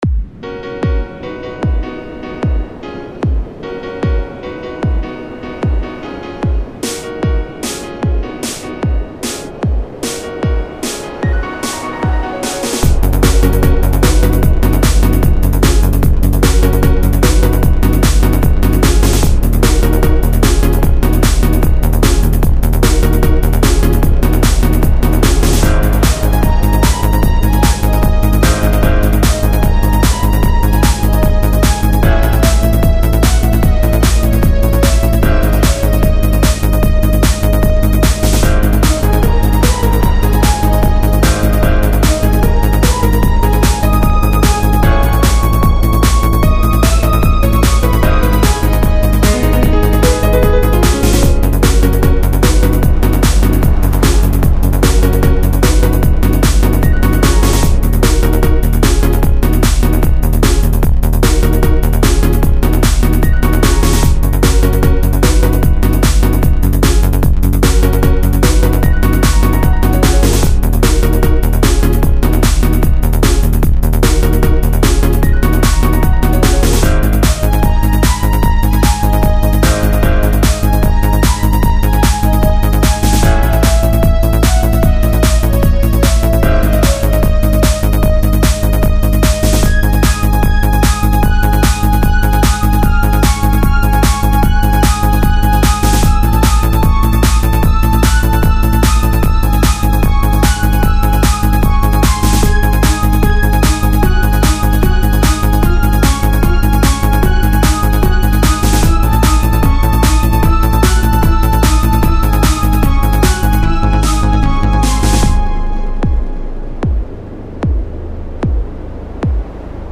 This is a remix